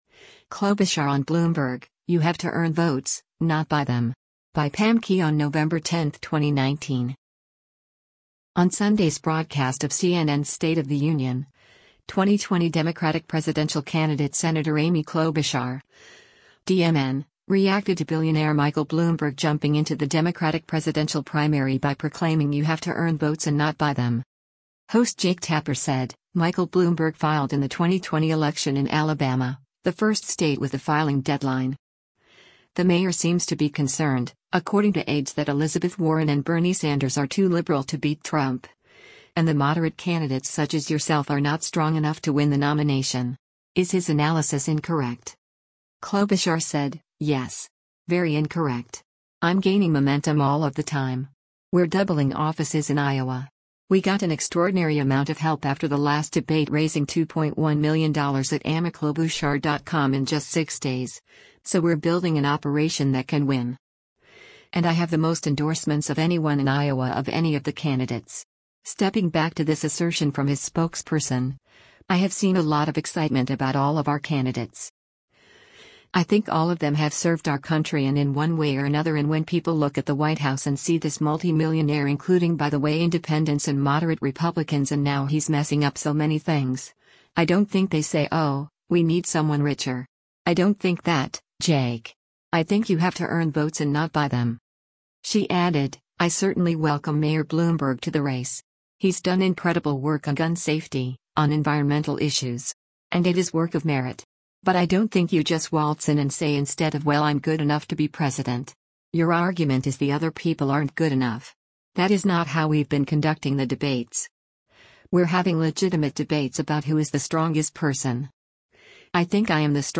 On Sunday’s broadcast of CNN’s “State of the Union,” 2020 Democratic presidential candidate Sen. Amy Klobuchar (D-MN) reacted to billionaire Michael Bloomberg jumping into the Democratic presidential primary by proclaiming “you have to earn votes and not buy them.”